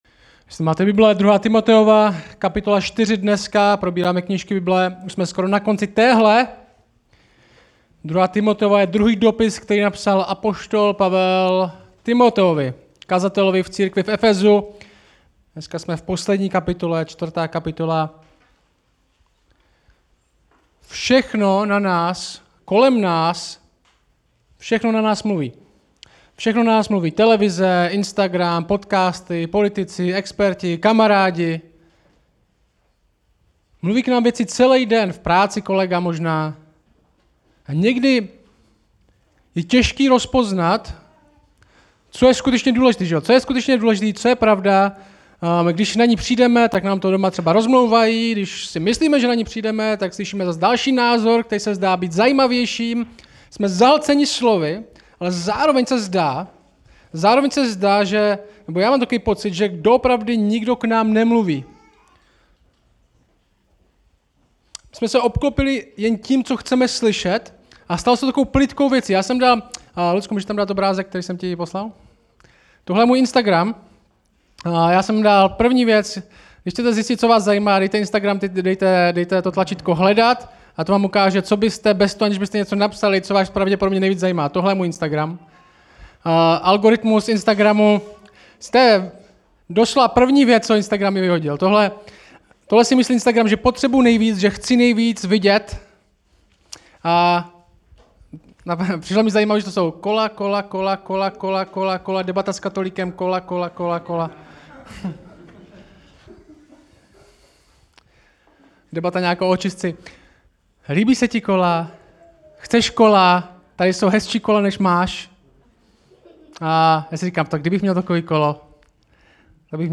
Kázání Stát pevně 2.